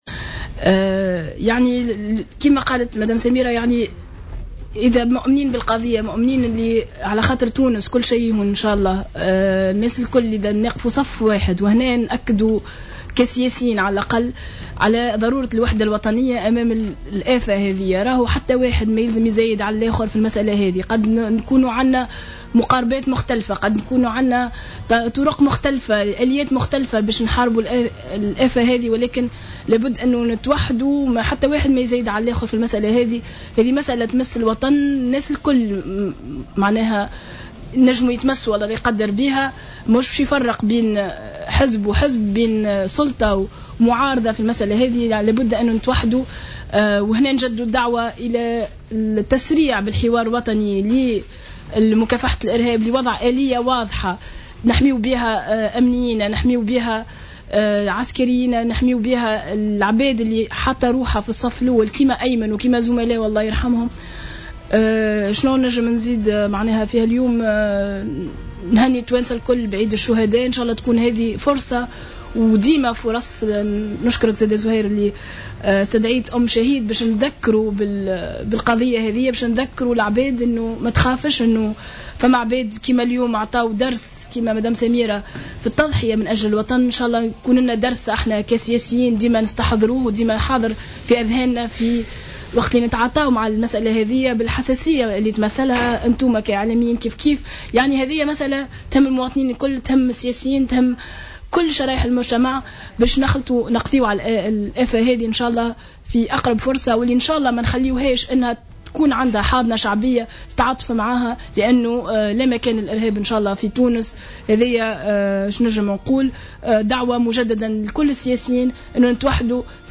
دعت إقبال مصدع،عن حزب المؤتمر من أجل الجمهورية ضيفة برنامج "بوليتيكا" اليوم الخميس إلى التسريع بإجراء الحوار الوطني لمكافحة الإرهاب والمصادقة على قانون الإرهاب .